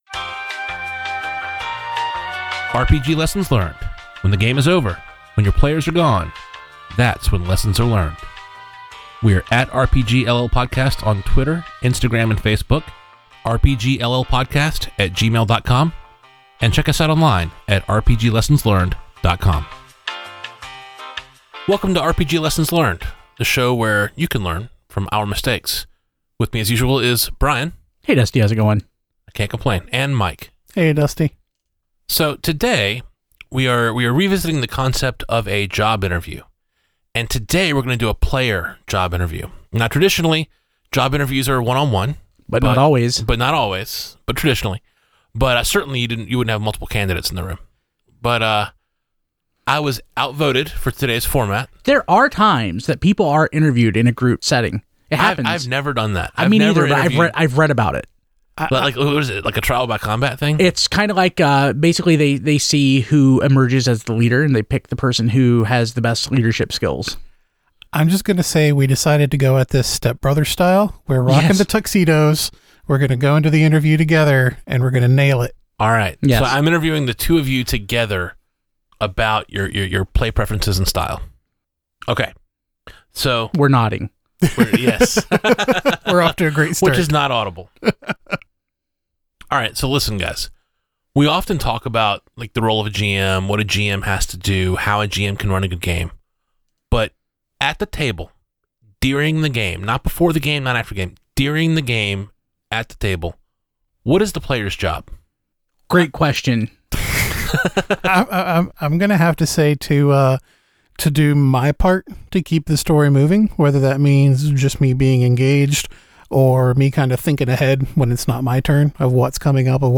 Player Job Interview!